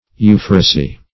euphrasy \eu"phra*sy\ ([=u]"fr[.a]*s[y^]), n. [NL. euphrasia,